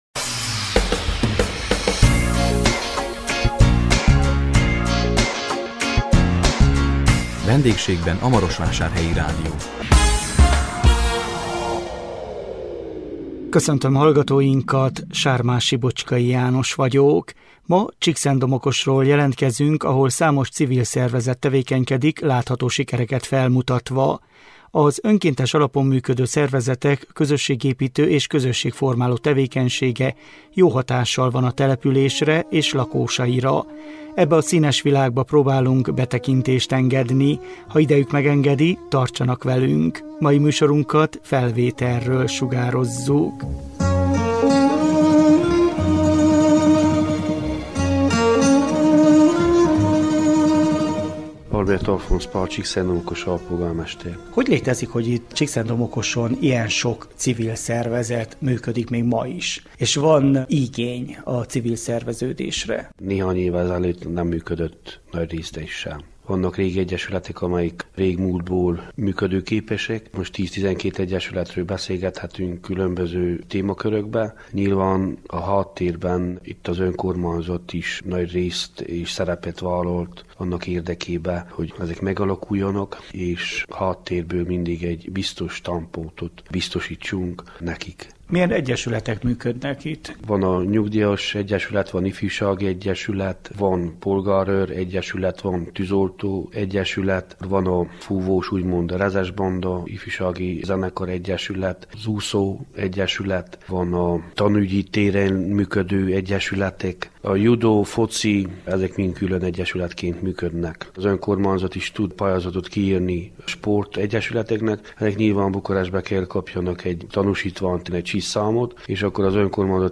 A 2025 április 10-én közvetített VENDÉGSÉGBEN A MAROSVÁSÁRHELYI RÁDIÓ című műsorunkkal Csíkszentdomokosról jelentkeztünk, ahol számos civil szervezet tevékenykedik látható sikereket felmutatva. Az önkéntes alapon működő szervezetek közösségépítő és közösség formáló tevékenysége jó hatással van a településre és lakósaira.